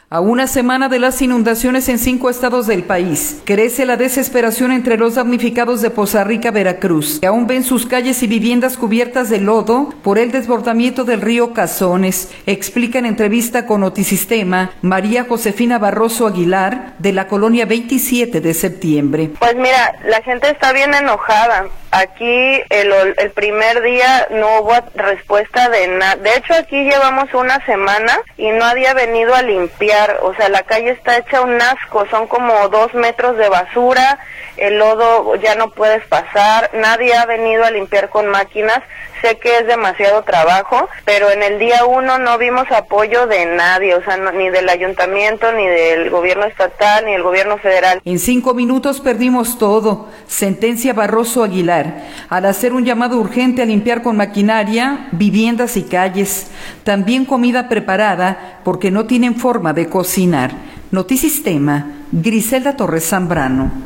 audio A una semana de las inundaciones en cinco estados del país, crece la desesperación entre los damnificados de Poza Rica, Veracruz, que aún ven sus calles y viviendas cubiertas de lodo por el desbordamiento del río Cazones, explica en entrevista con Notisistema